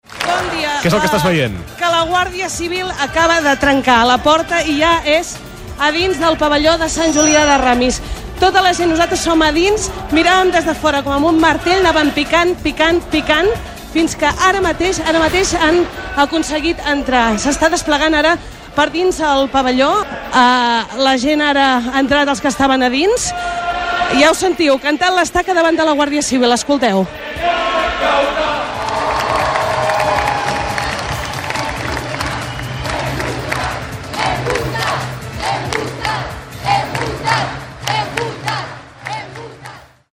Especial referèndum: transmissió des de Sant Julià de Ramis - Catalunya Ràdio, 2017